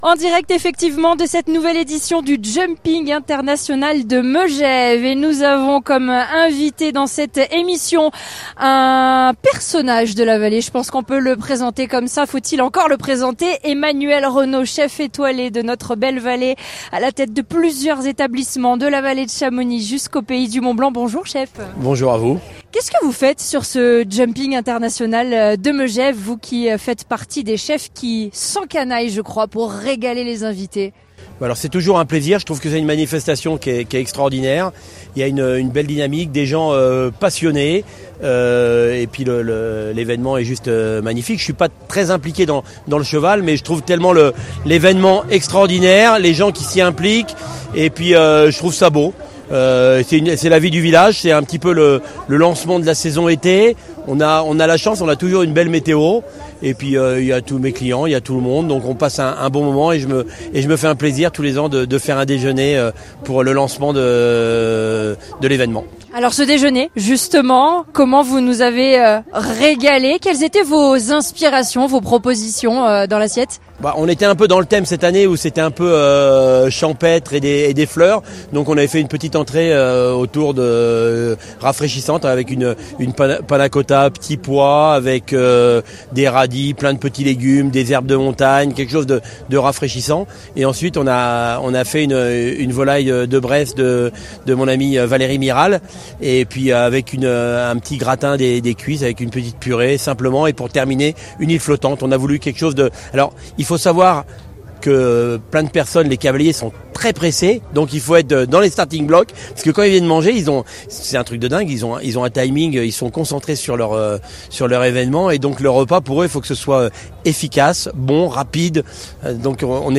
Partenaire de l’événement, nous étions en émission spéciale en direct, pour faire vivre aux auditeurs toute l’ambiance de cette grande fête du sport et de l’élégance.
Emmanuel Renaut, chef triplement étoilé et Meilleur Ouvrier de France
Interview